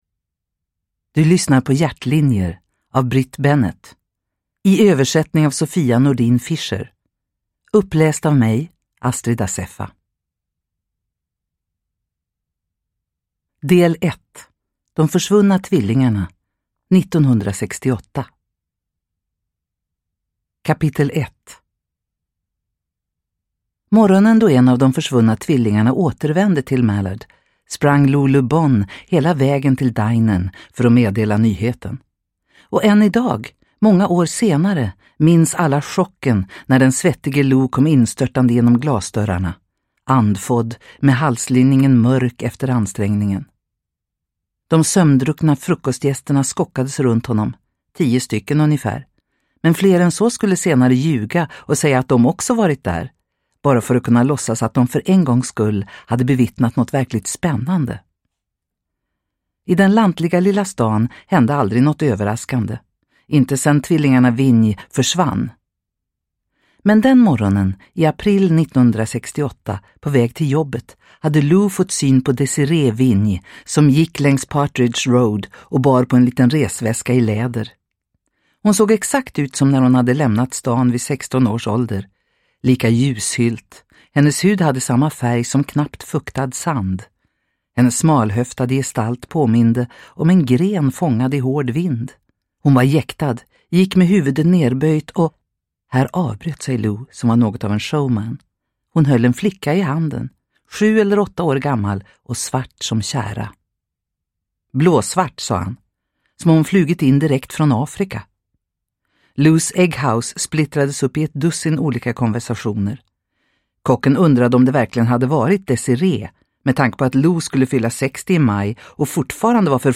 Hjärtlinjer – Ljudbok – Laddas ner